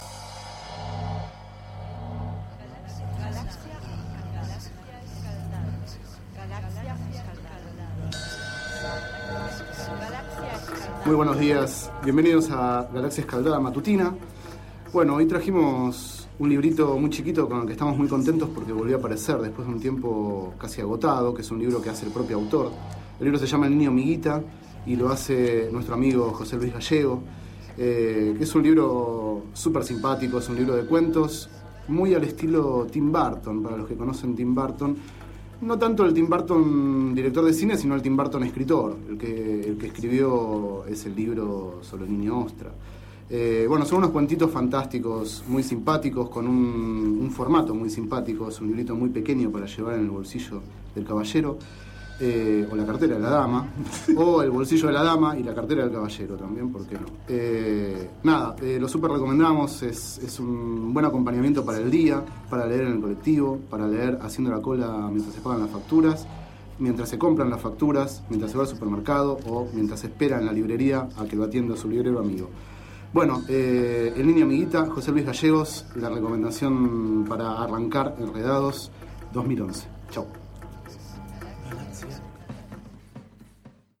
Este es el 1º micro radial, emitido en el programa Enredados, de la Red de Cultura de Boedo, por FMBoedo, realizado el 05 de marzo de 2011, sobre el libro El niño Miguita, de José Luis Gallego.